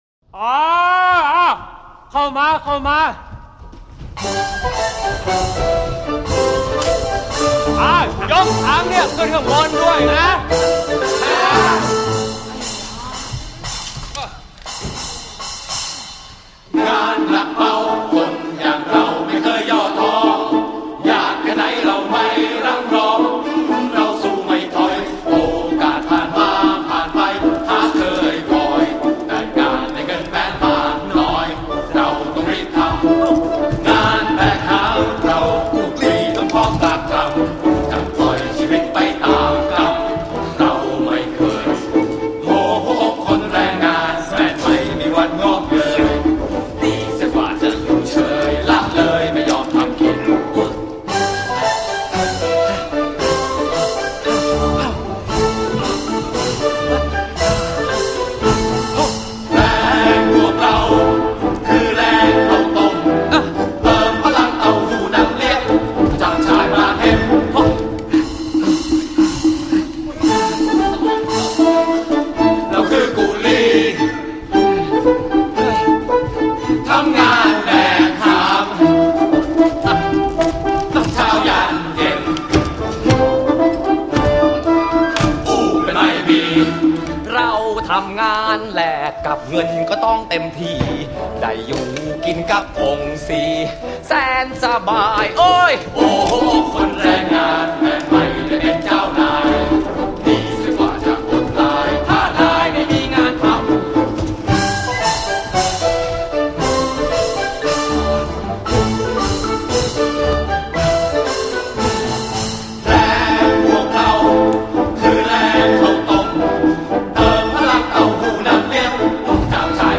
ละครเพลงสื่อผสม